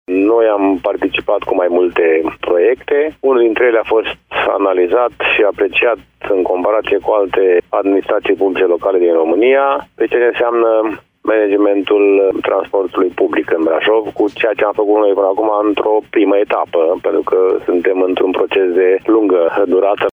Proiectul care i-a adus Municipiului Brașov acest premiu a fost „Managementul informatizat al sistemului de transport în comun“, implementat de Primăria Brașov. Primarul George Scripcaru: